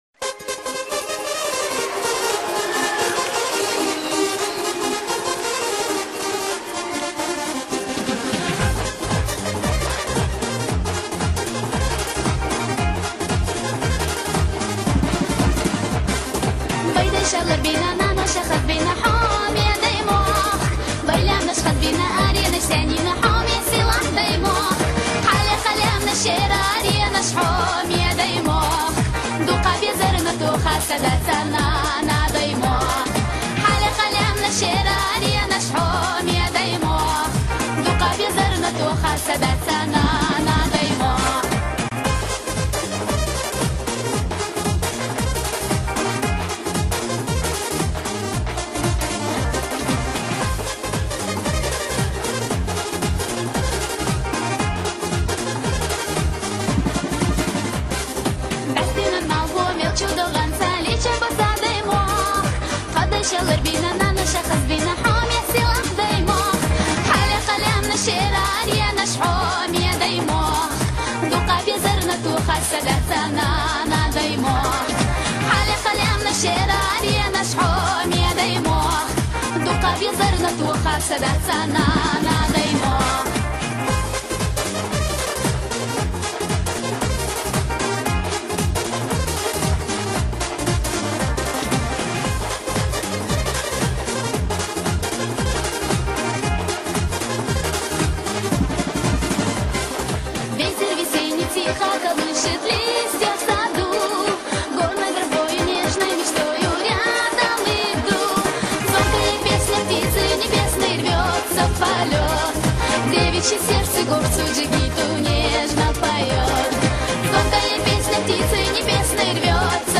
российская чеченская певица и танцовщица